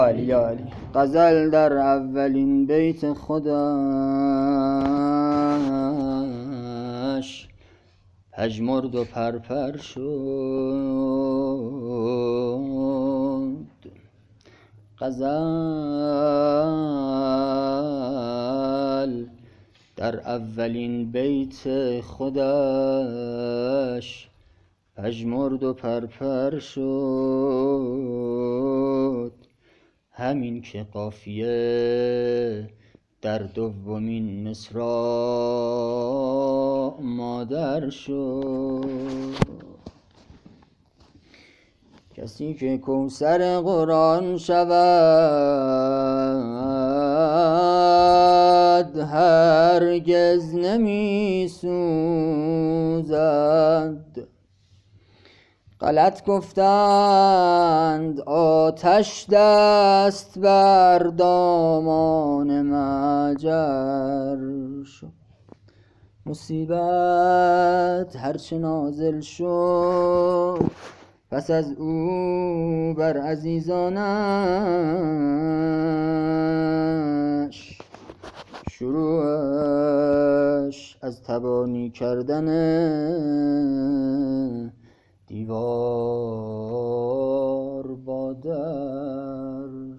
شعر پایانی